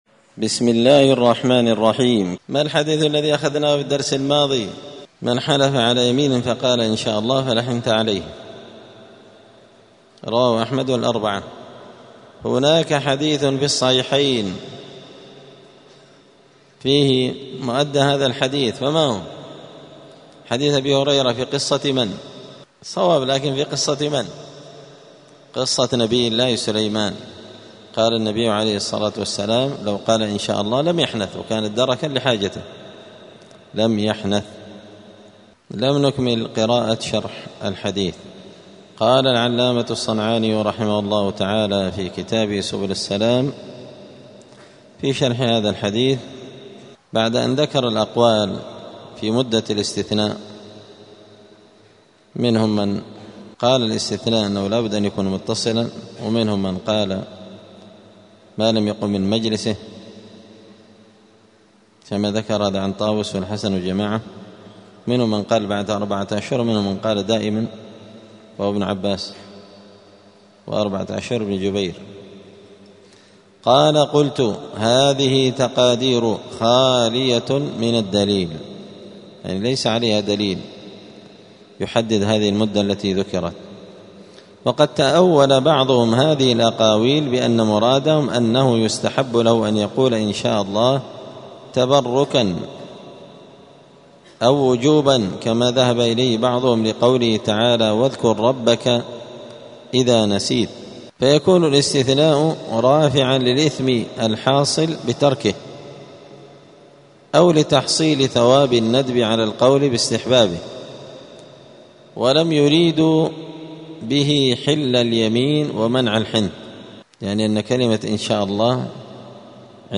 *الدرس السادس (6) {الاستثناء في اليمين}*